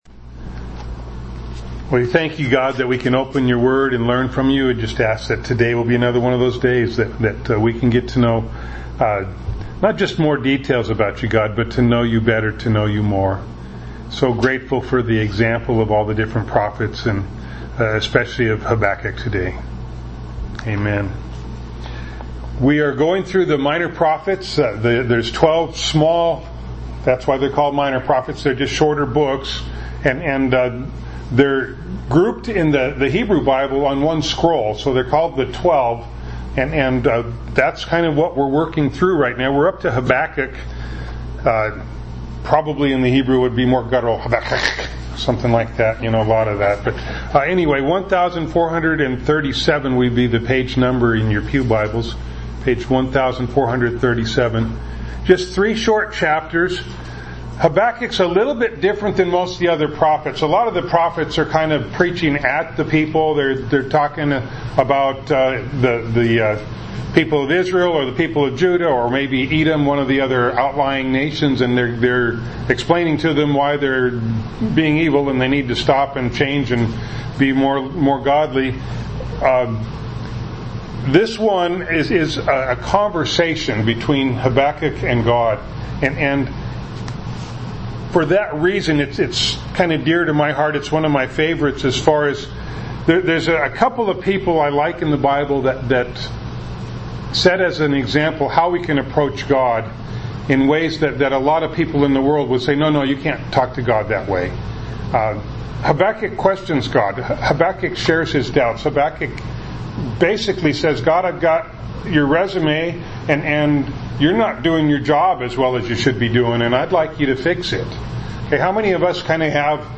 Living By Faith – Skykomish Community Church